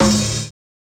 SNARE_SWEET.wav